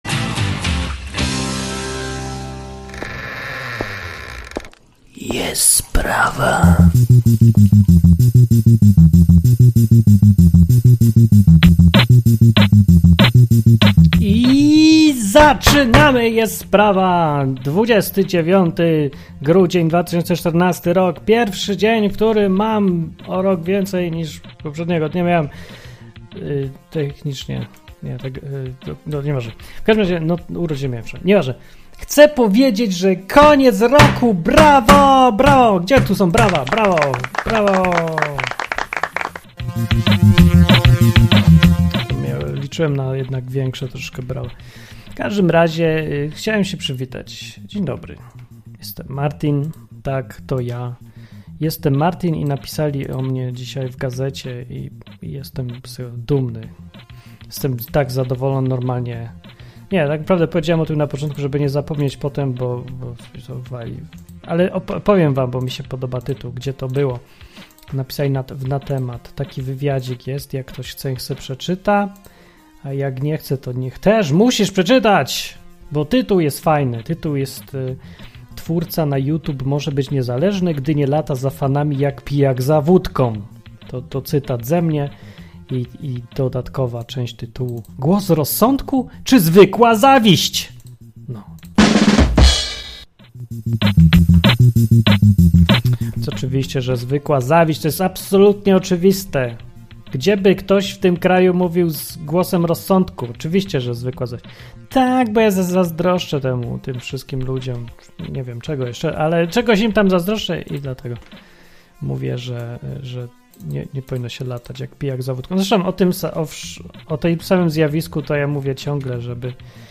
Świetna piosenka na końcu!